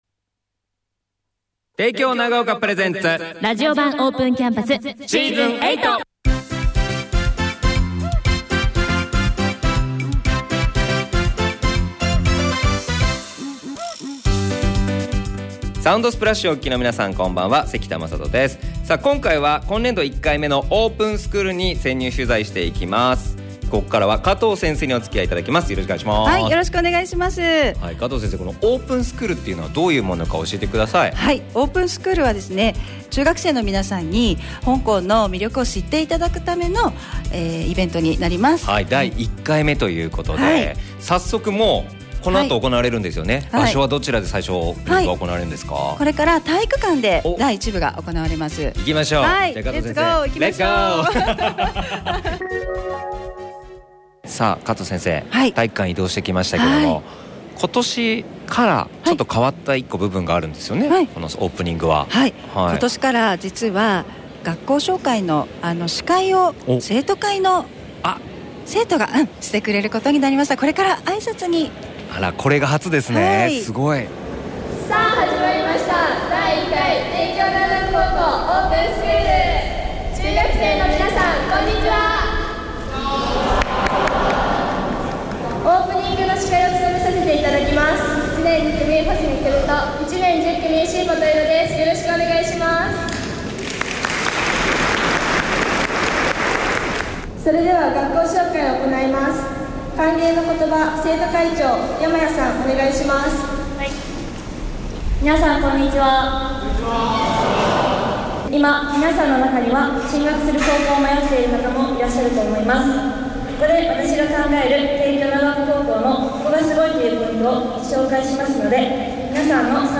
まずは、オープニングでの学校紹介の様子をぜひお聴きください。
0806-帝京長岡ラジオ版オープンキャンパス.mp3